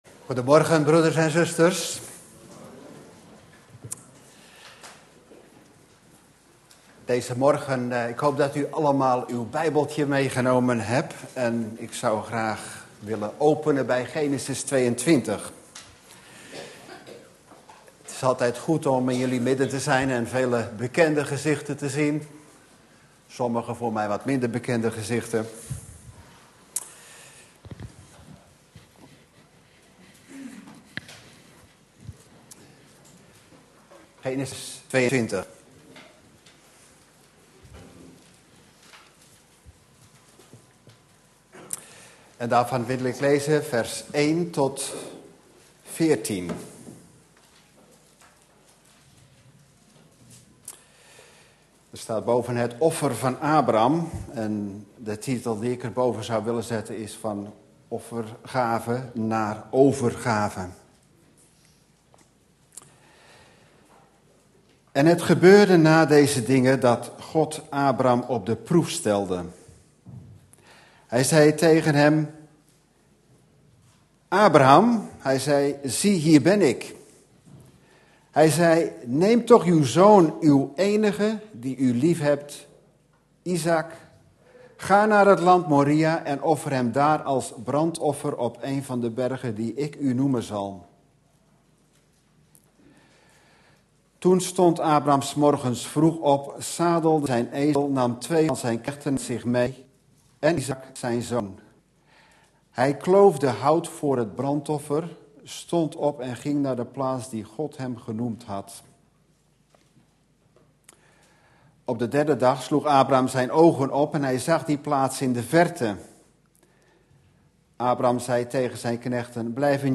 In de preek aangehaalde bijbelteksten (Statenvertaling)Genesis 22:1-141 En het geschiedde na deze dingen, dat God Abraham verzocht; en Hij zeide tot hem: Abraham!